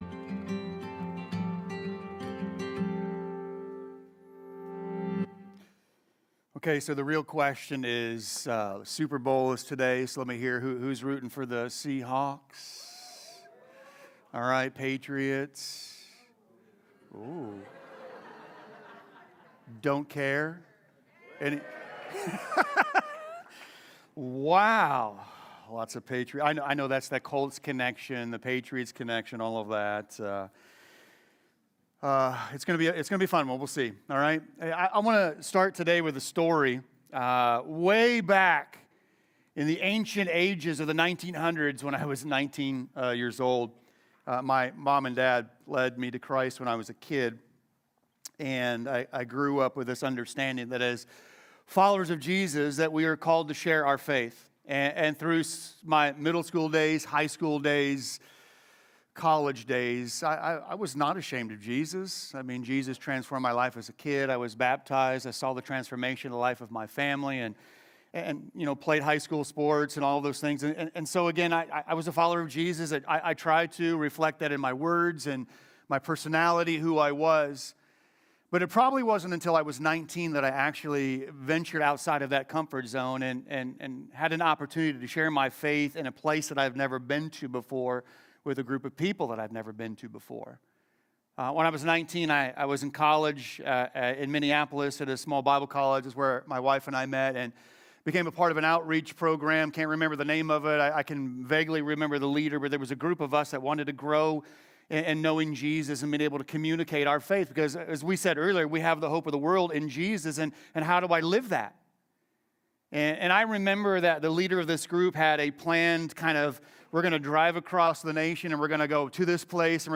Sermons – Commonway Church